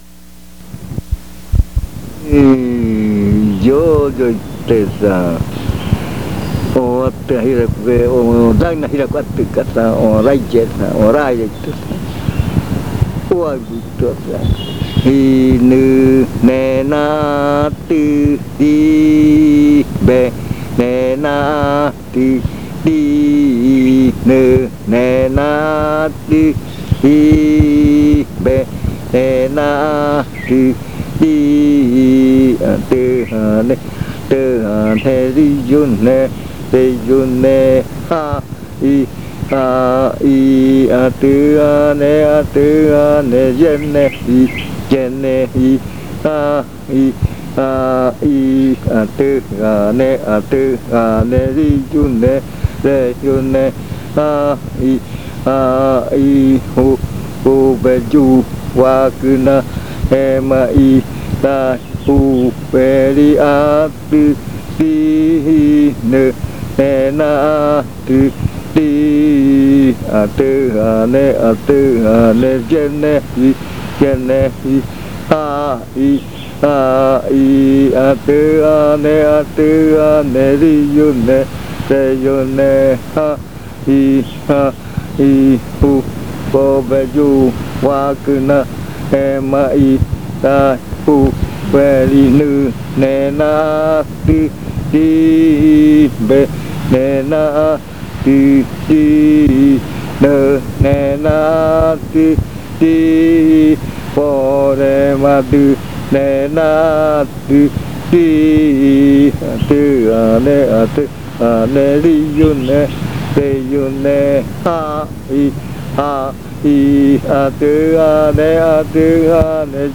Canto sin adivinanza lo canta el cantor para animar a bailar los bailarines. Dice la canción: tráeme las buenas energías.
Chant without riddle, it is sung to encourage the dancers to dance.